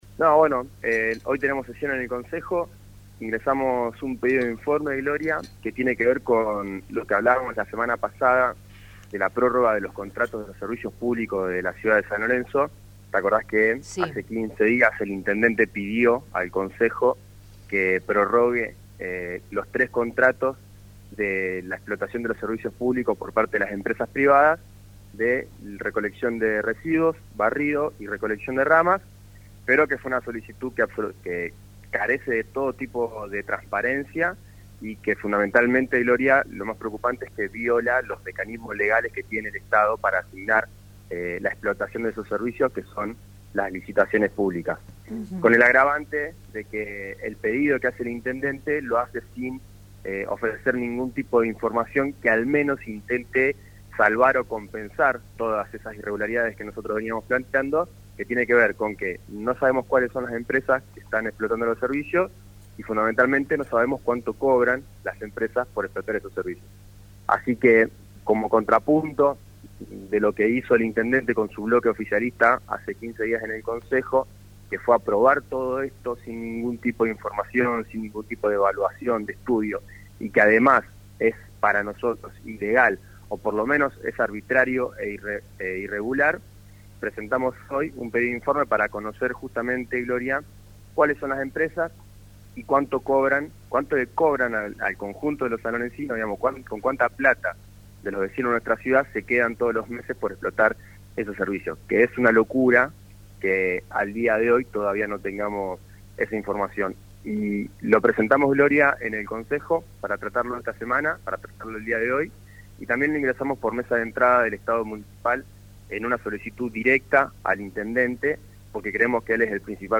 Martín Cerdera en diálogo con «Un día de Gloria»